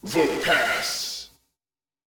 🌲 / midnight_guns mguns mgpak0.pk3dir sound announcer
vote_passed_00.wav